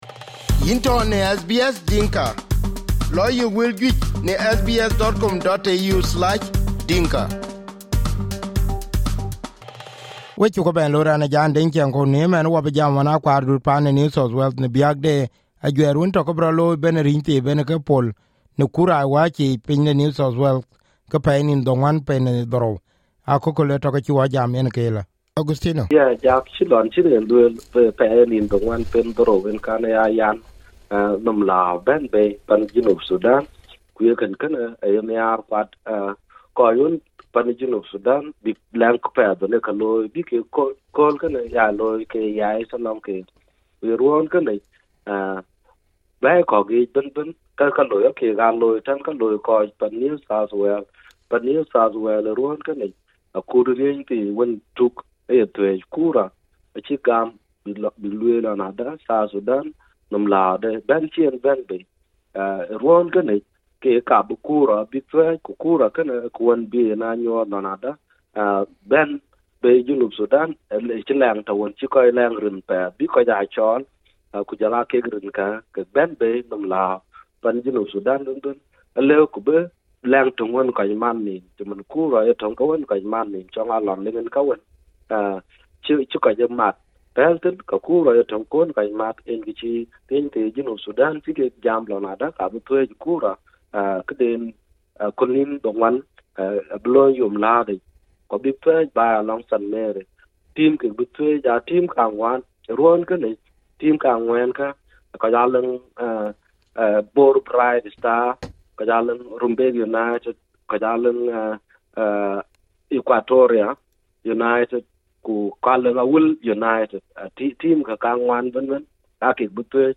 in SBS Radio studio in Sydney